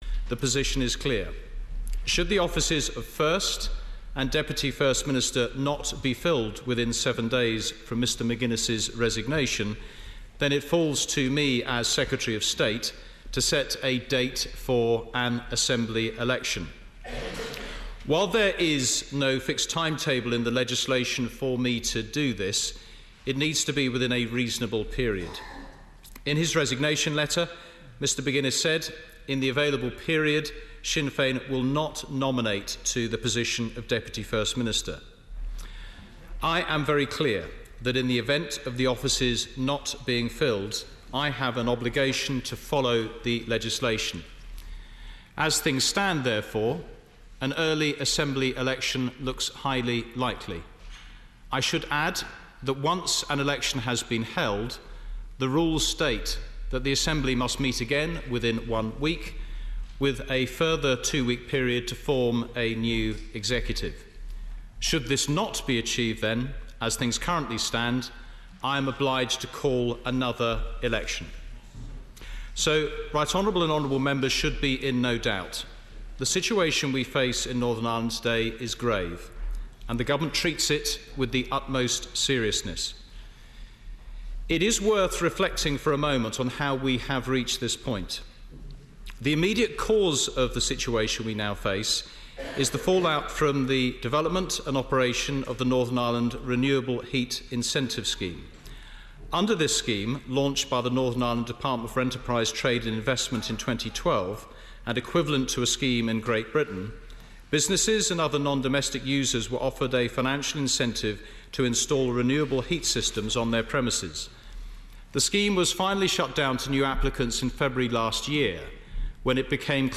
LISTEN: NI secretary James Brokenshire updates MPs on the situation since the resignation of Martin McGuinness